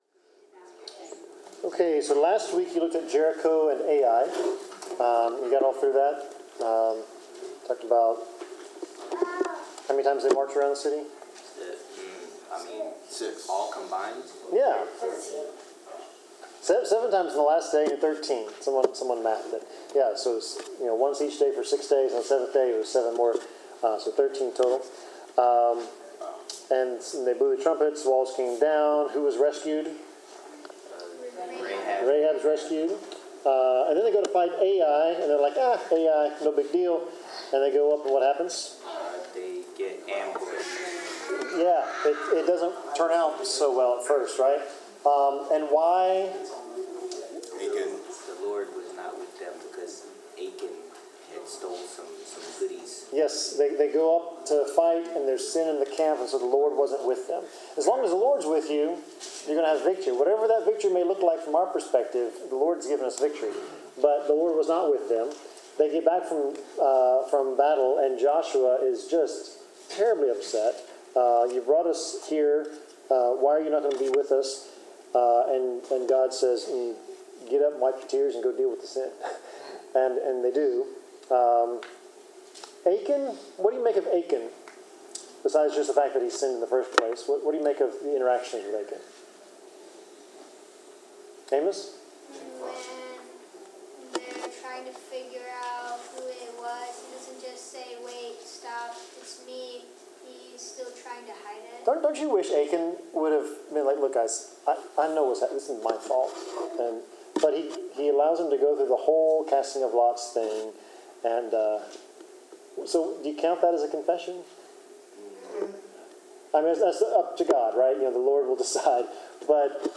Bible class: Joshua 9-10
Passage: Joshua 9-10 Service Type: Bible Class